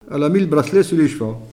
Enquête Arexcpo en Vendée
Locution